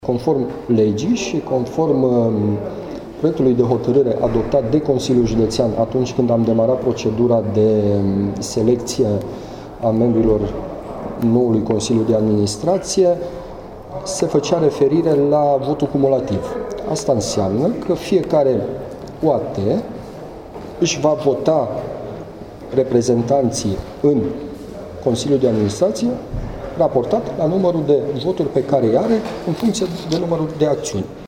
Urgenţa era că astăzi expiră termenul legal în care proiectul putea fi aprobat, spune preşedintele Consiliului Judeţean Caraş-Severin, Silviu Hurduzeu: Silviu Hurduzeu Consiliul Judeţean Caraş-Severin este acţionar majoritar la Aqua Caraş. Societatea a avut un profit brut la finalul anului trecut de 670 mii lei, iar în 2017, şi-a propus să îşi îmbunătăţească rezultatele.